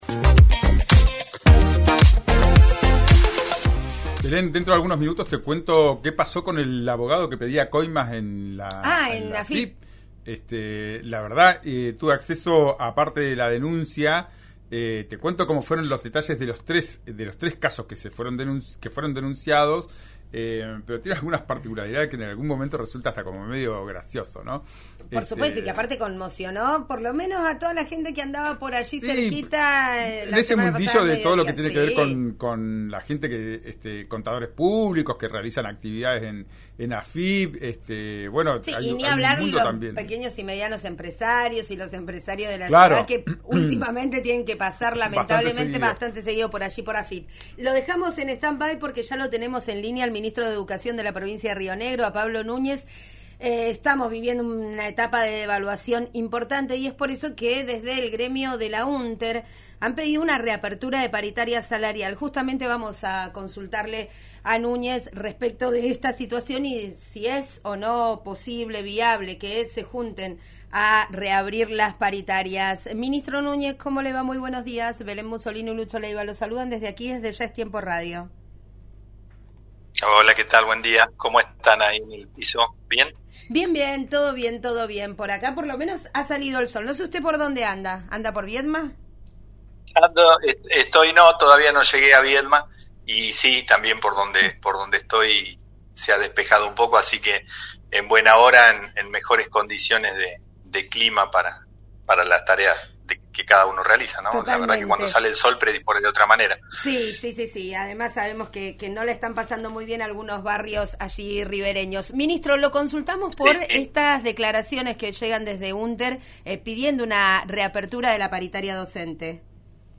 En respuesta al reclamo sindical, Pablo Núñez -ministro de Educación de Río Negro- dialogó en «Ya es tiempo» por RÍO NEGRO RADIO y dio su visión acerca de las demandas docentes.